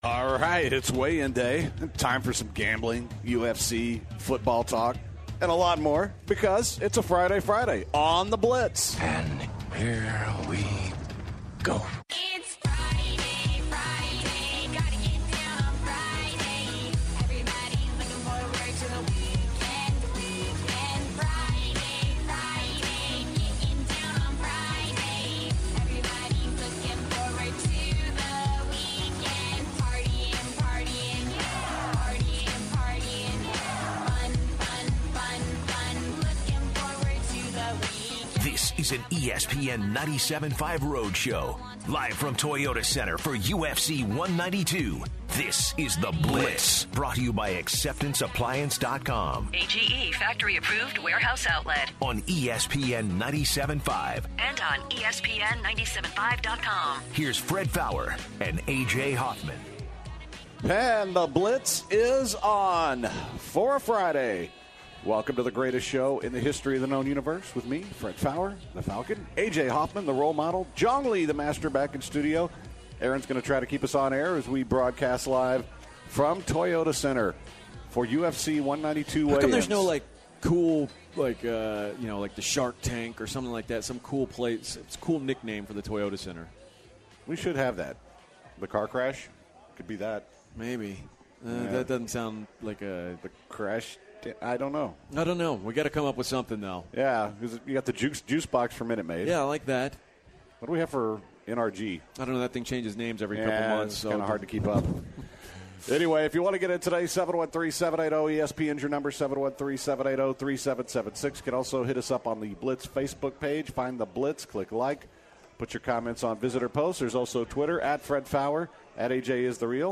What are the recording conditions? broadcasting live from the Toyota Center for the UFC weigh ins.